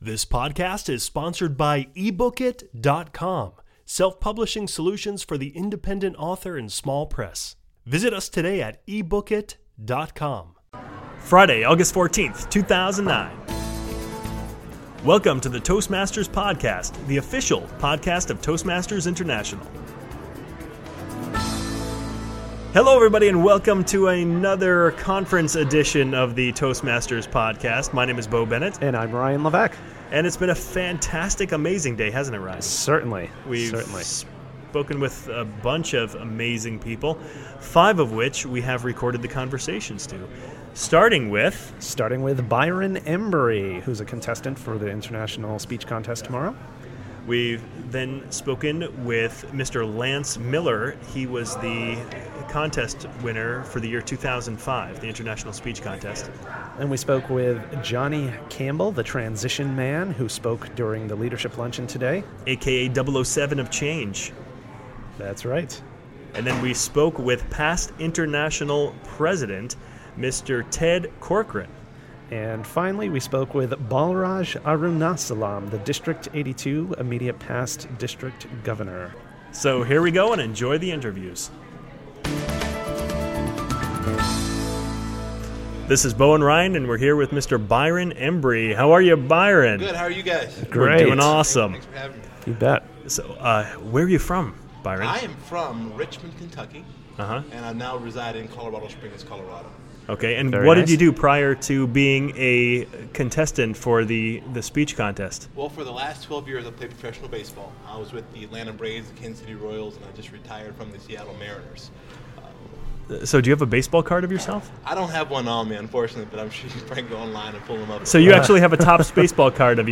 Part 2 of our coverage at the 2009 Toastmasters International Convention.